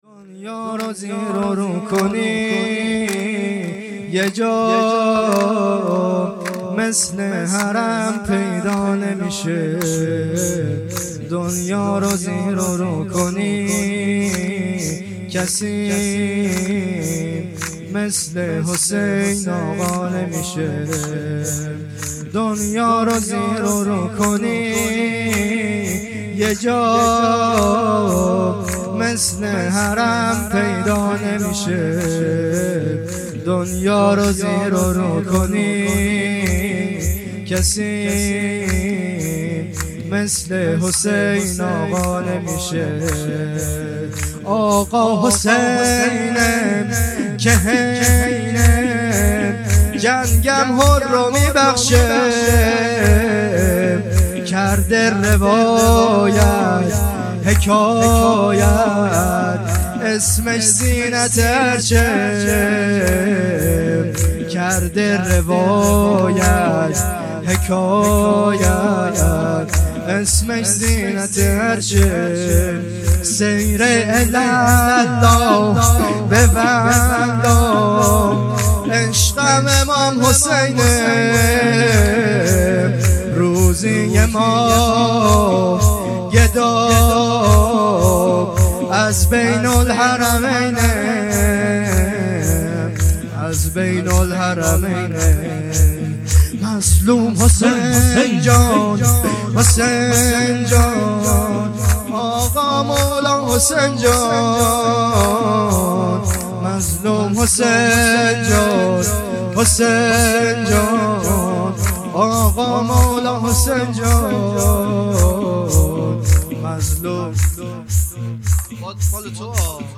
شب لیله الرغائب 28 دی 1402